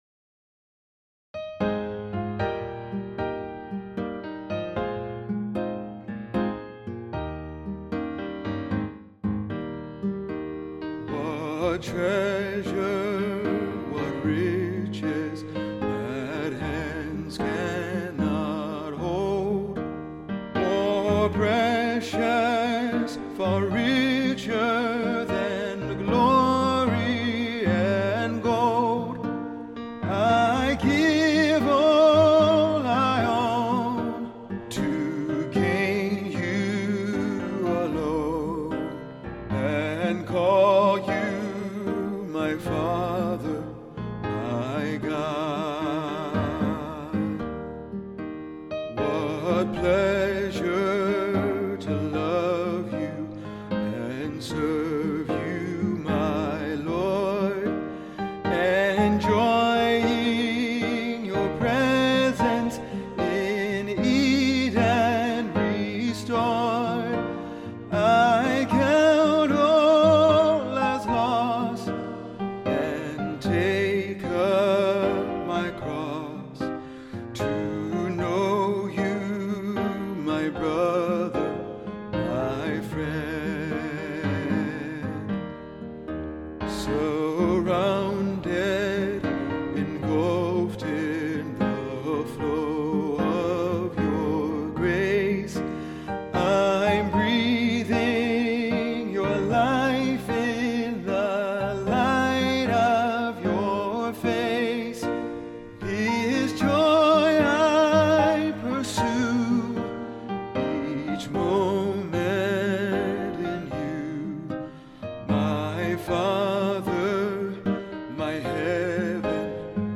Hymn: Treasure